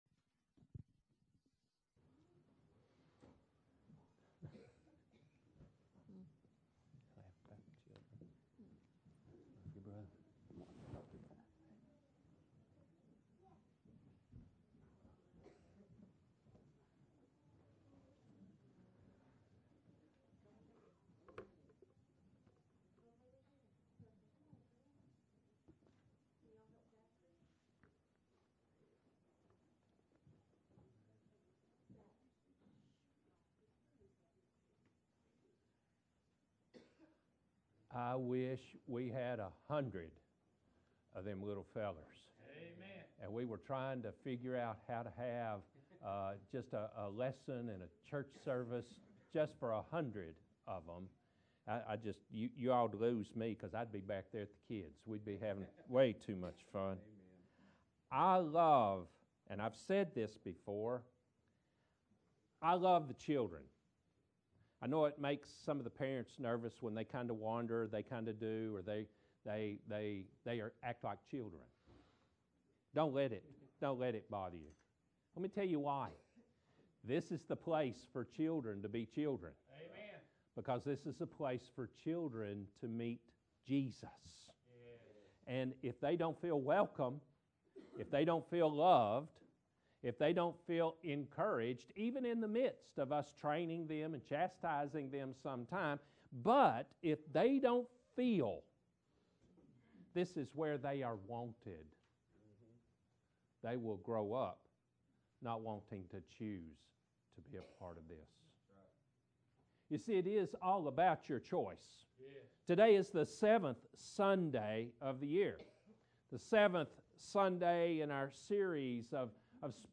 Passage: Acts 2: 41-47 Service Type: Morning Worship « Spiritual Disciplines